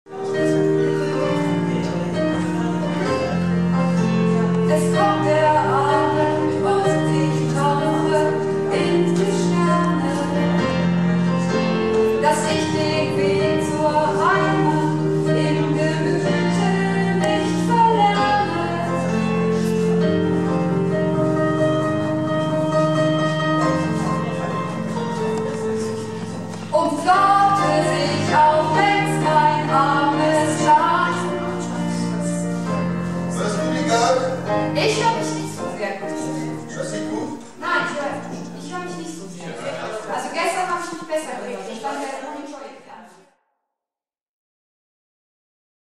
Theater - "Du musst es wagen - Sünner Tegenstöten word nümms deftig" am 23.09.12 in Filsum
01 - Es kommt der Abend (Soundcheck)